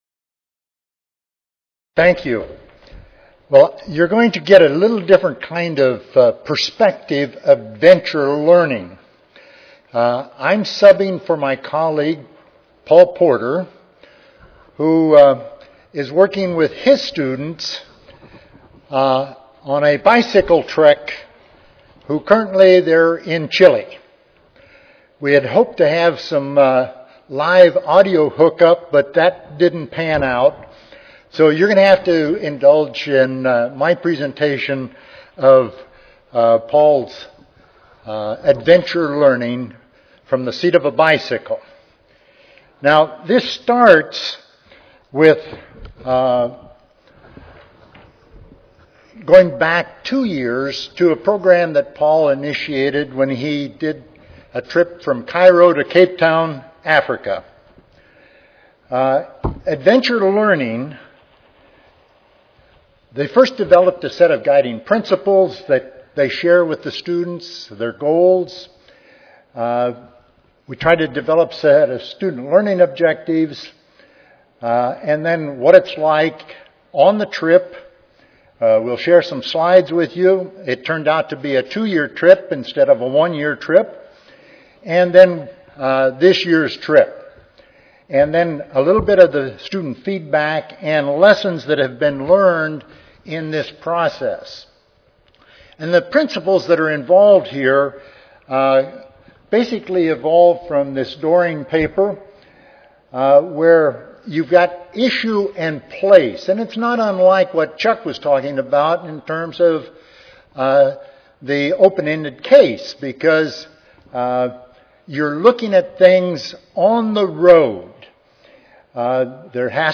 University of Minnesota Recorded Presentation Audio File